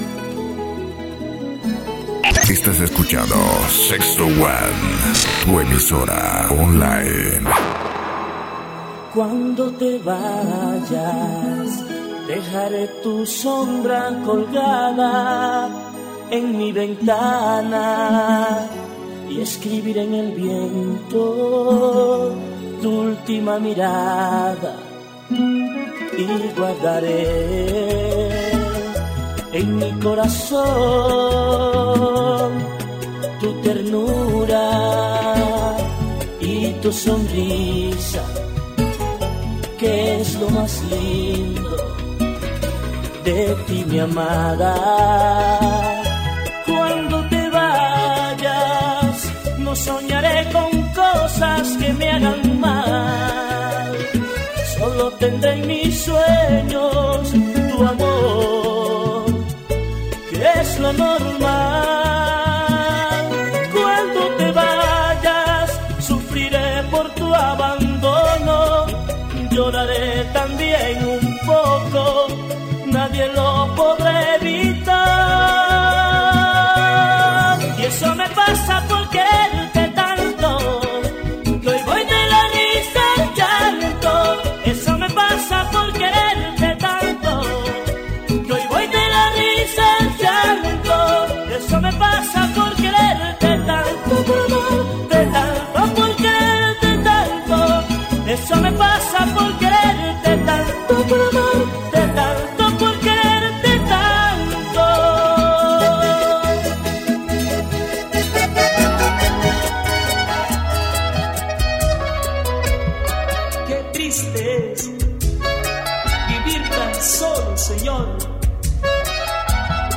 Mixes